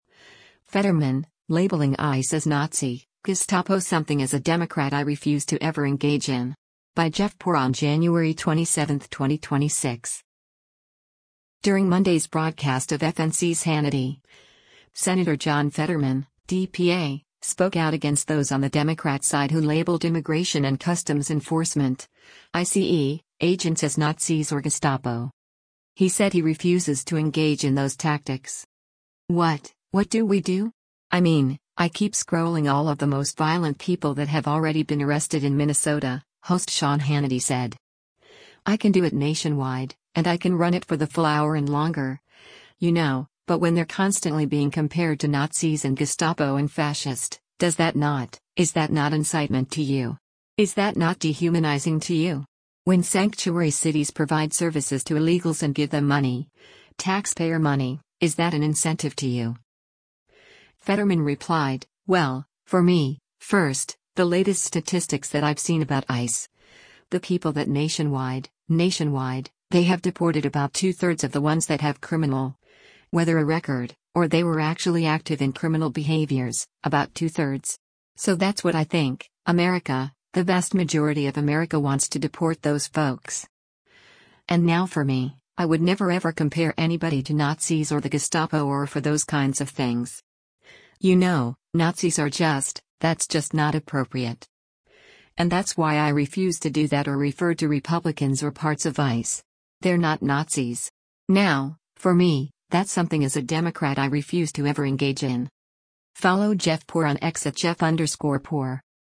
During Monday’s broadcast of FNC’s “Hannity,” Sen. John Fetterman (D-PA) spoke out against those on the Democrat side who labeled Immigration and Customs Enforcement (ICE) agents as “Nazis” or “Gestapo.”